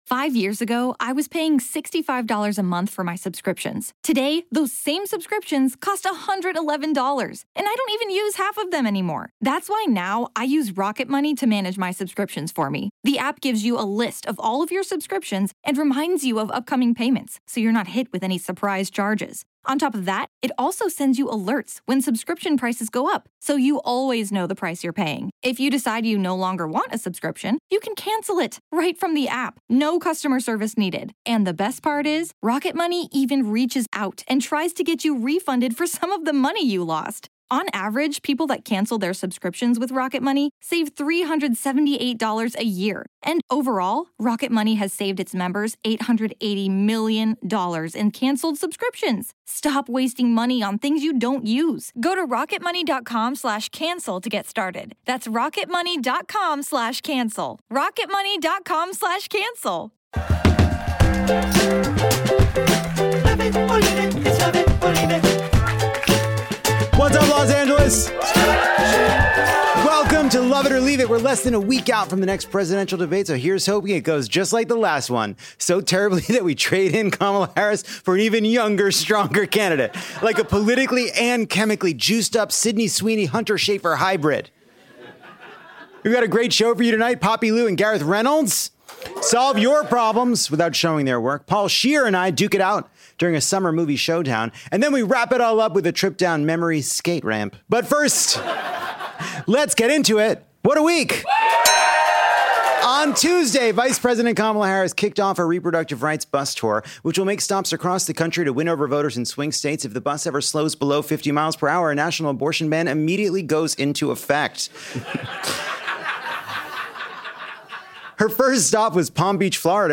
Lovett or Leave It is back at Dynasty Typewriter to sweat through the first heat wave of fall! Poppy Liu and Gareth Reynolds stop by to solve our audience’s problems, Paul Scheer gets a little twisted, and we all raise a glass of Fruitopia to the best decade there ever was.